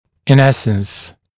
[ɪn ‘esns][ин ‘эснс]по существу